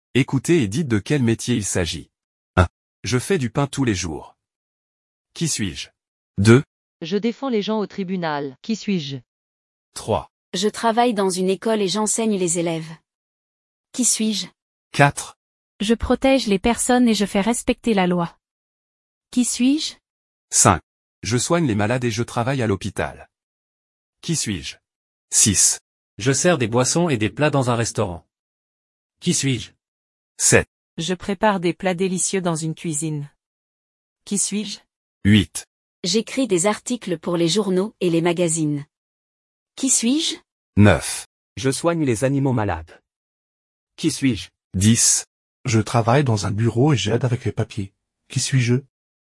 Compréhension orale : devinettes